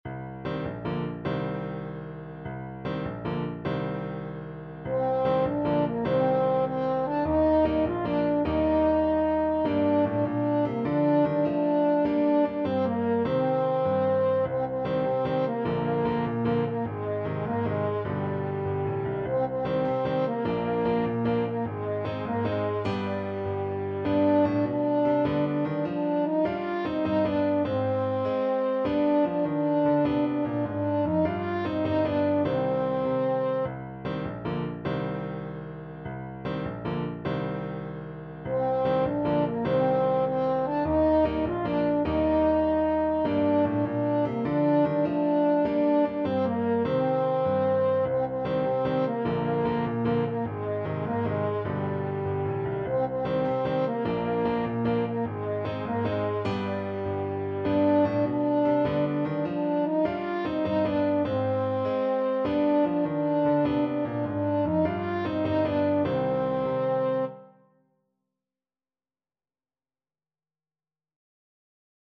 French Horn
Traditional Music of unknown author.
6/8 (View more 6/8 Music)
C minor (Sounding Pitch) G minor (French Horn in F) (View more C minor Music for French Horn )
With energy .=c.100
Classical (View more Classical French Horn Music)